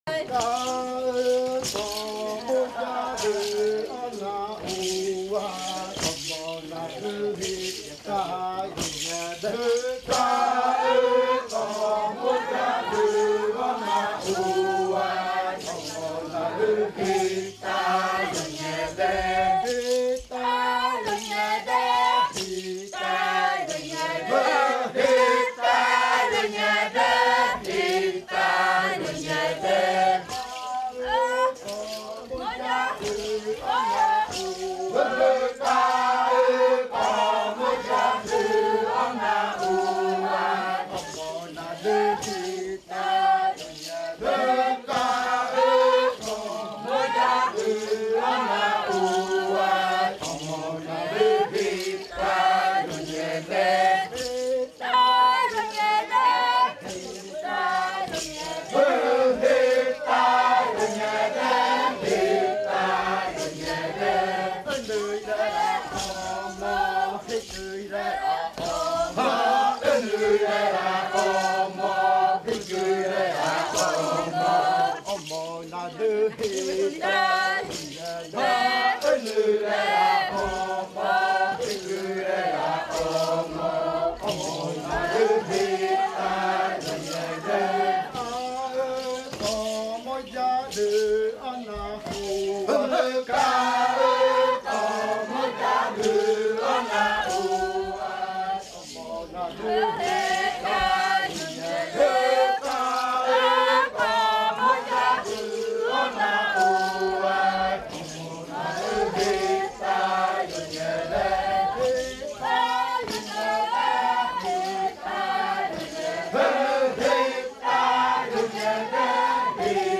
Canto de la variante muinakɨ
Leticia, Amazonas
con el grupo de cantores bailando en la Casa Hija Eetane. Este canto se interpretó en el baile de clausura de la Cátedra de Lenguas "La lengua es espíritu" de la UNAL, sede Amazonia.
with the group of singers dancing at Casa Hija Eetane. This song was performed at the closing dance ritual Language Lectureship “Language is Spirit”, of the UNAL Amazonia campus.